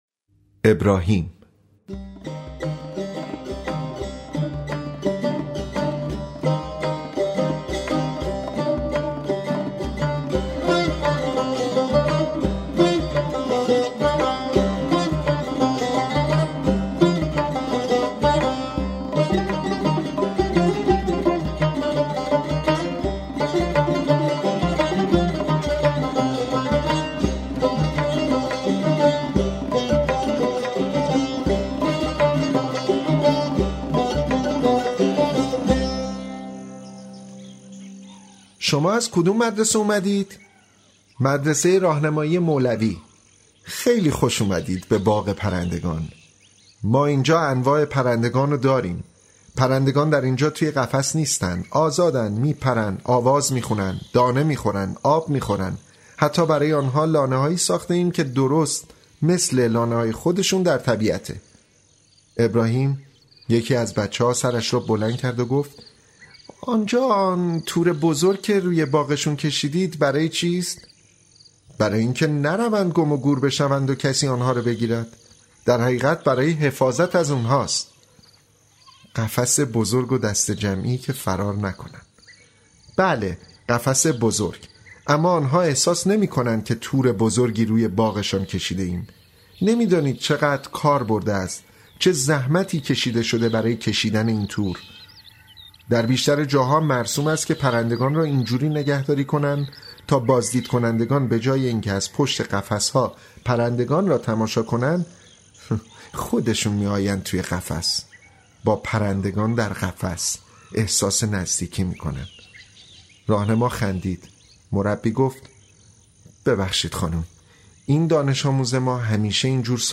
«زیر نور شمع»‌ عنوان کتاب صوتی با صدای مهدی پاک دل بازیگر سینماست که آثار هوشنگ مرادی کرمانی را خوانده،‌ این کتاب از سوی مؤسسه نوین کتاب گویا منتشر شده است.
این قصه‌ها نگاهی متفاوت به زندگی آدم‌ها و شرایط فرهنگی و اجتماعی آنها دارد و به‌دلیل سادگی و روانی در روایت بسیار دلنشین است. این کتاب صوتی با آهنگ‌سازی مهدی زارع روانه بازار شده است.